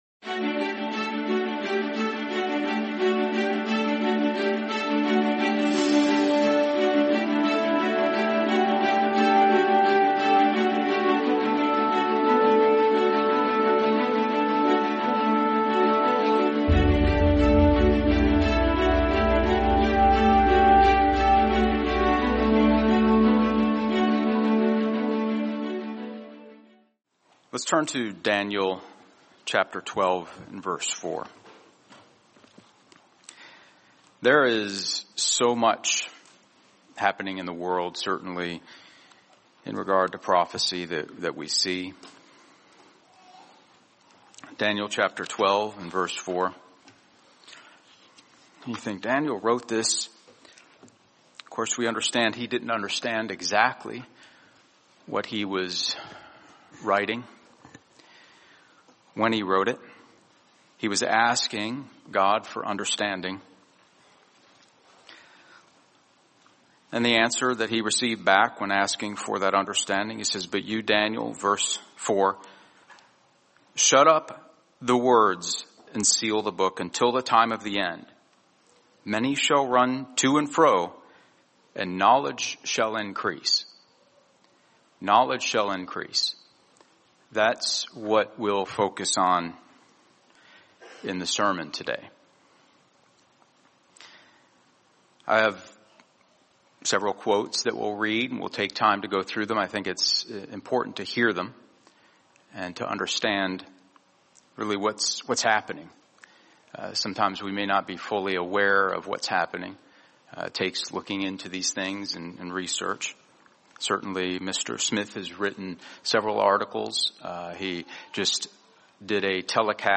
Sermon Use AI With Eyes Wide Open